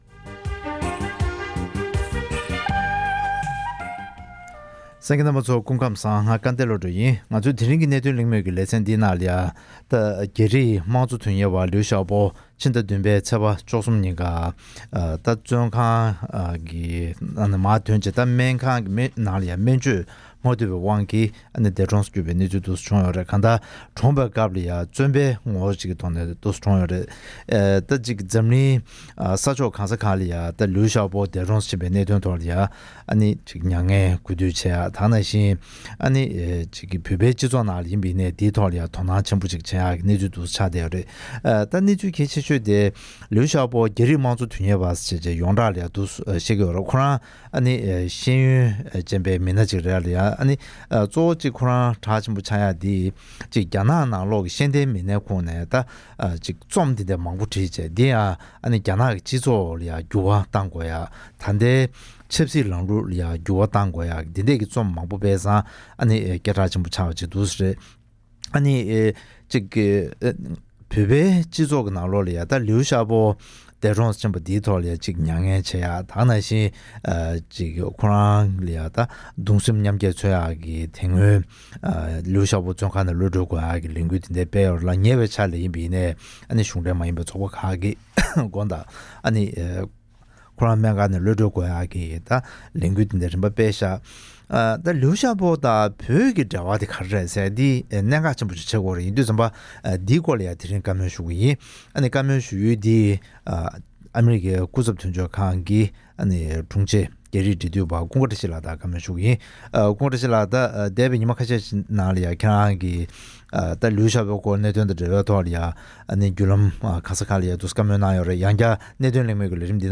ཉིན་ཤས་སྔོན་འདས་གྲོངས་སུ་གྱུར་བའི་ནོར་བེལ་ཞི་བདེའི་གཟེངས་རྟགས་ཐོབ་མཁ་ན་ལིའུ་ཞའོ་པོ་དང་བོད་ཀྱི་འབྲེལ་བ་སོགས་ཀྱི་ཐད་གླེང་མོལ།